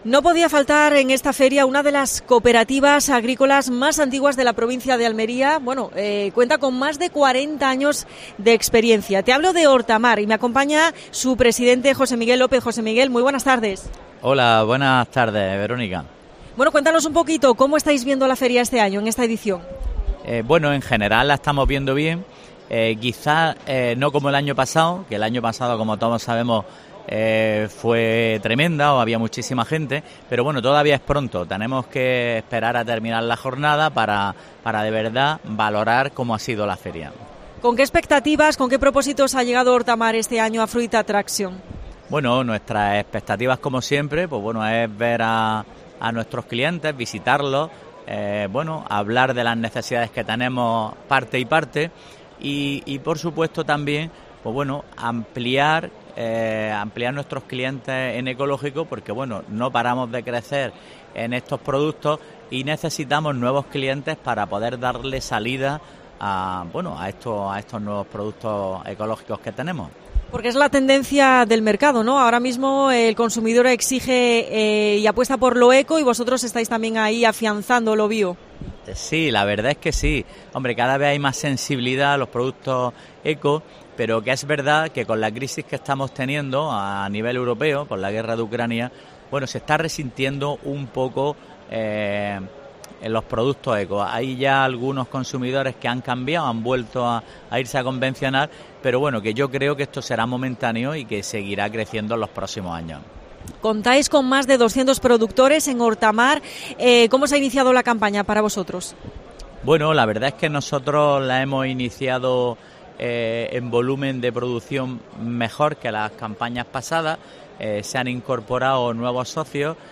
AUDIO: Especial desde Fruit Attracion en COPE Almería.